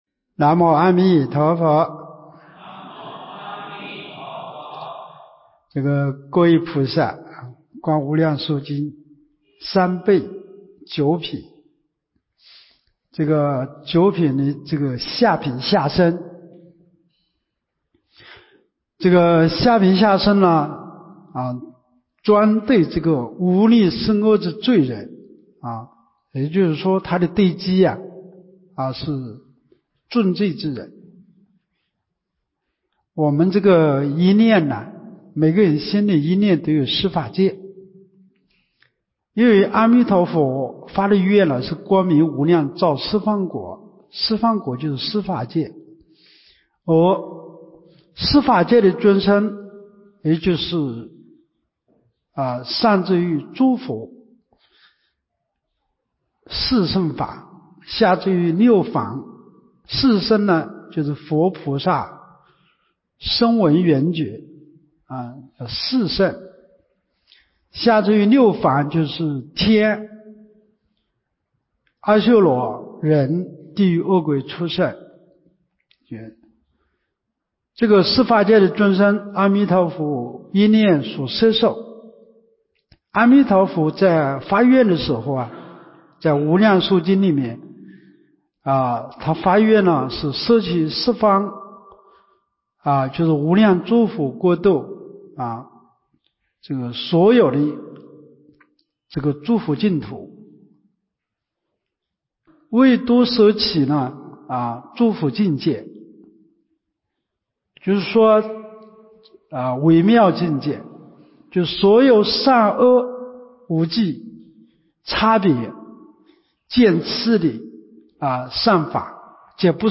24年陆丰学佛苑冬季佛七（九）